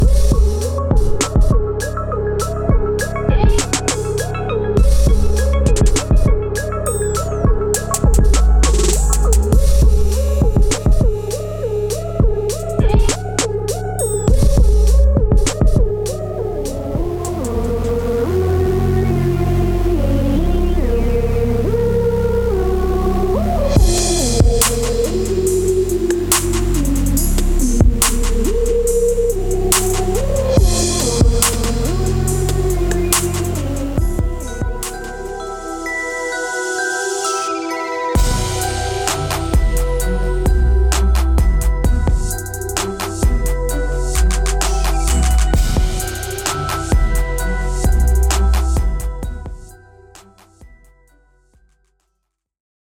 探索了在全世界图表中占主导地位的EDM，极简和低速节奏氛围。
还包括完整的打击杆和击打音轨，以提供完整的拍子灵活性。